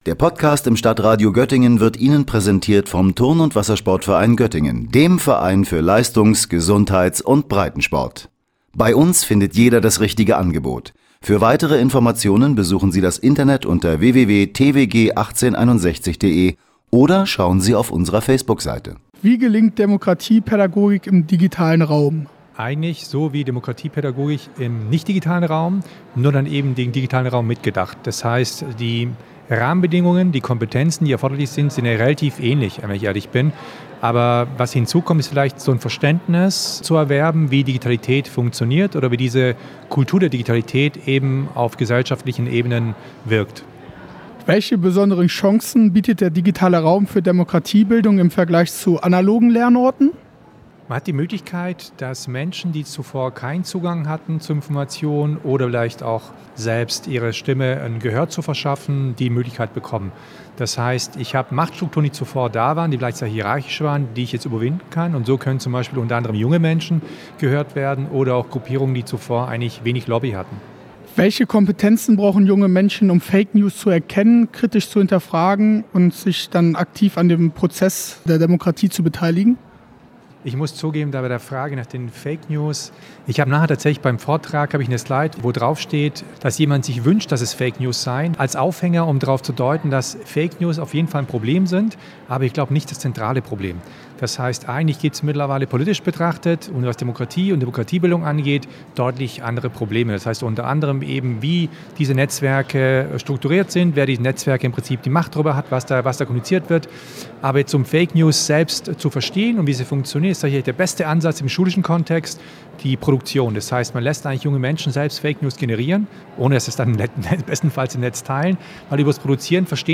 Mit dem aufsteigenden digitalen Raum, verstärken sich auch die Herausforderungen in der Demokratiepädagogik. In Bovenden fand zu diesem Thema gestern die Bildungskonferenz Südniedersachsen statt.